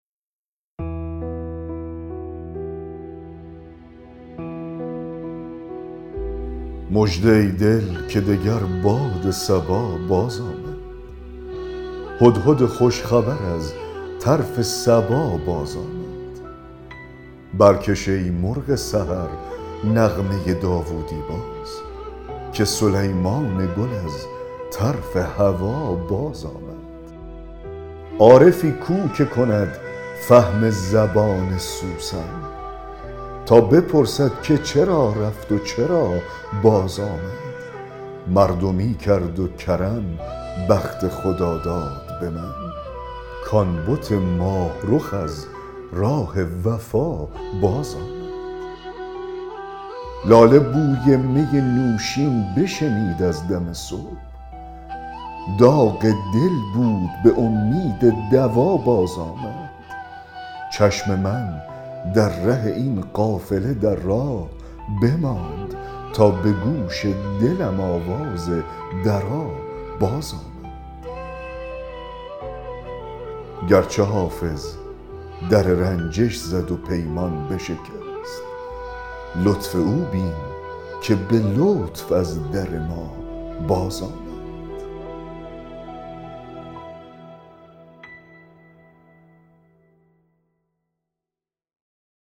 دکلمه غزل 174 حافظ
دکلمه-غزل-174-حافظ-مژده-ای-دل-که-دگر-باد-صبا-باز-آمد.mp3